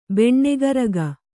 ♪ beṇṇegaraga